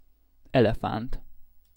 Ääntäminen
US : IPA : [ˈe.lɪ.fənt]